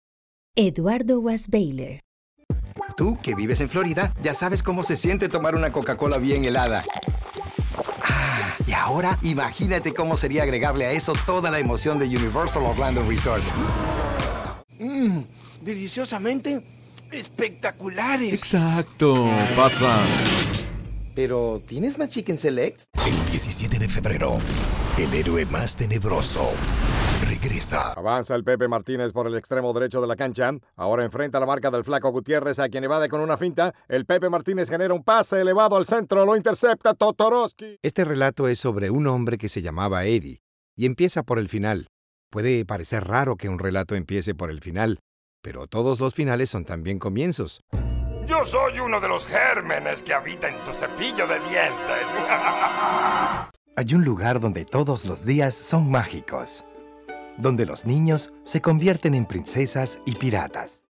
Spanish Demo